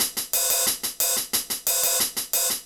Air Hats.wav